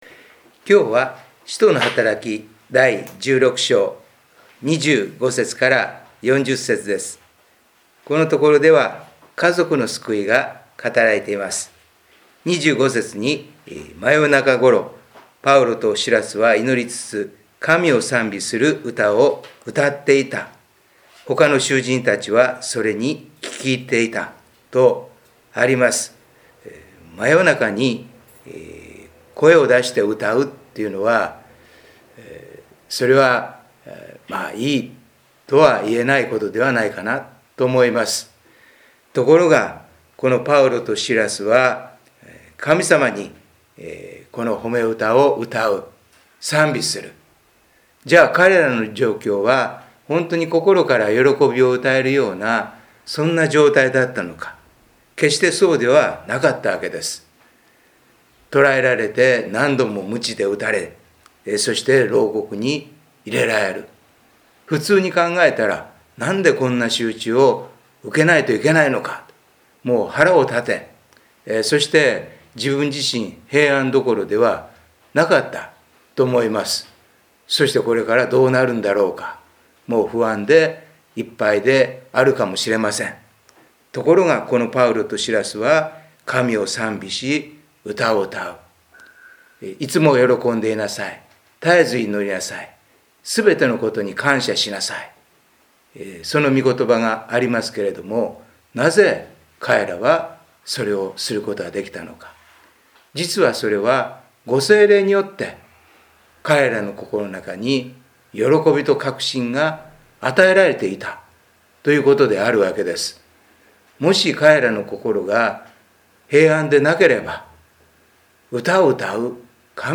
礼拝メッセージ│日本イエス・キリスト教団 柏 原 教 会